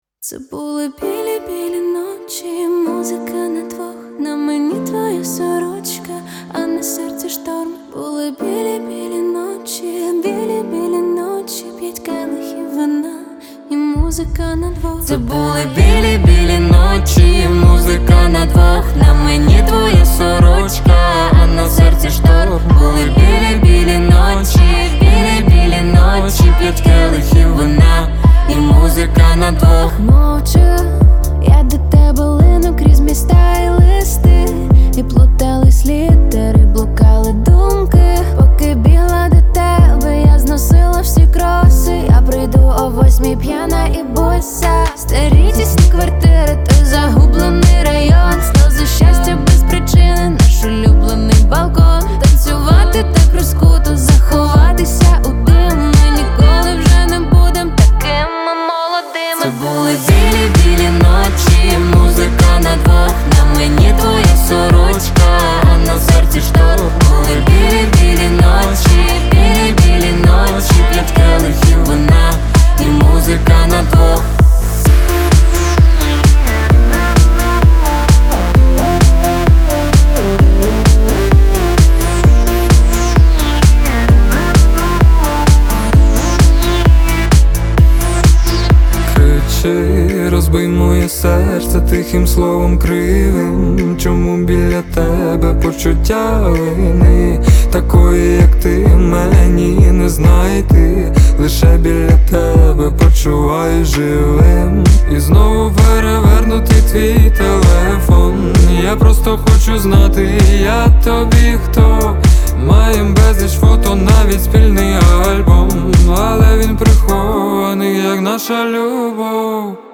Жанр: УКРАЇНСЬКІ